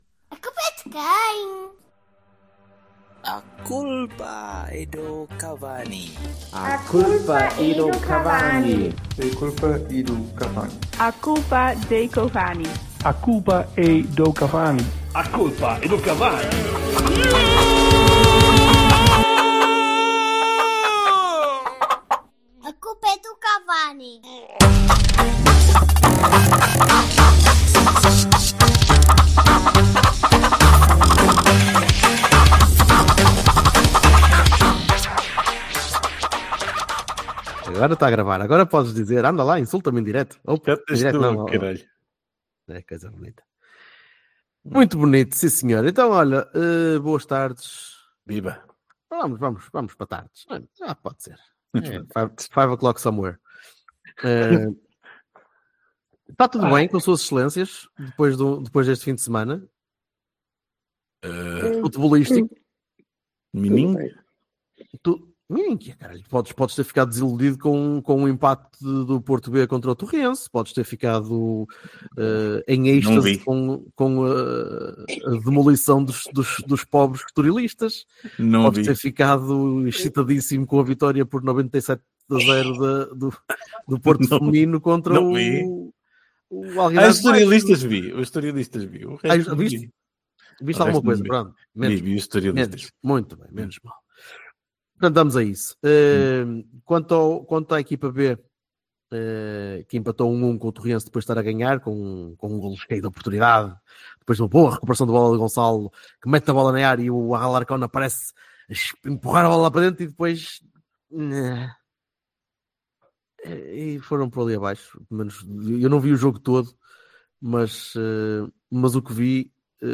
Três portistas. Três bloggers. Um microfone reles.